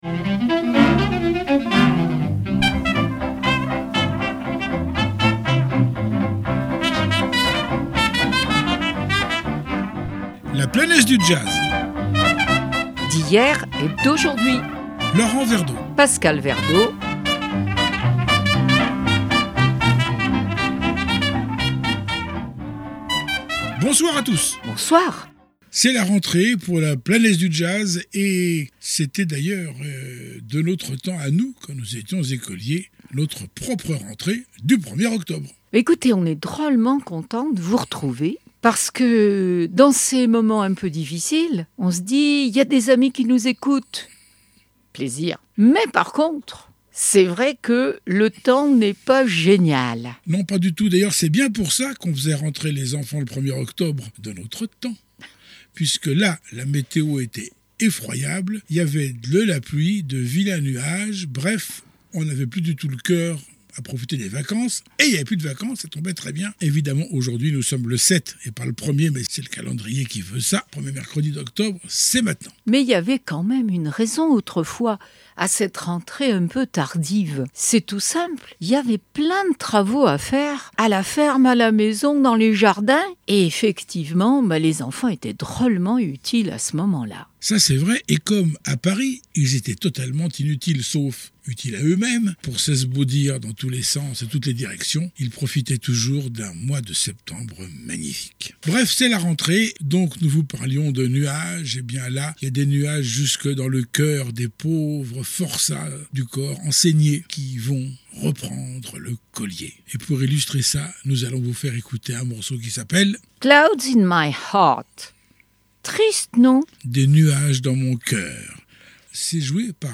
Emission sur le Jazz